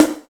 81 SNARE 2.wav